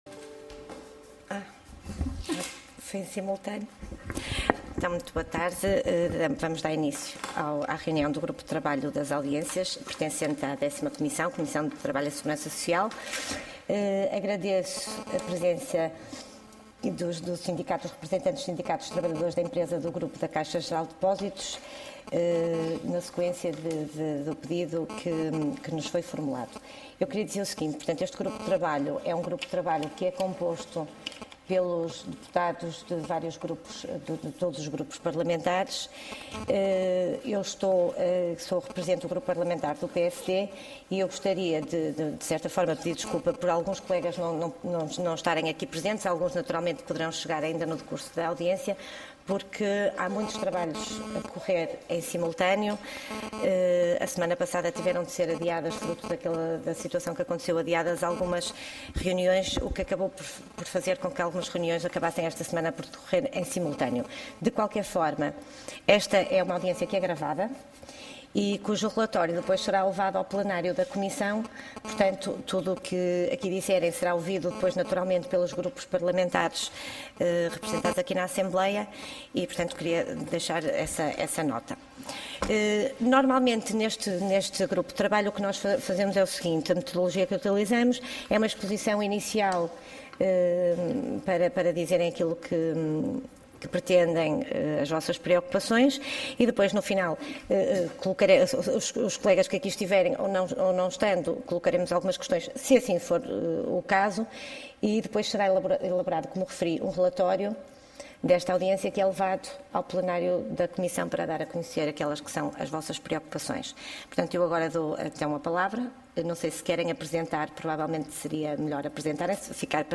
Audiência Parlamentar